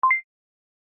collect.mp3